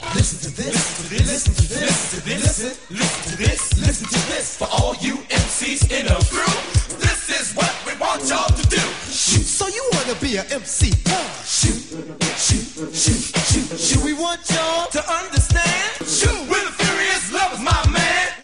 My hunch is that the hi-f sibilants of today’s young inner-city Londoners share a common ancestry with Eminem’s – in African American hip hop culture. Here are Grandmaster Flash and the Furious Five in the early 80s: